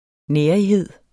Udtale [ ˈnεːɐ̯iˌheðˀ ]